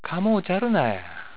ためになる広島の方言辞典 か．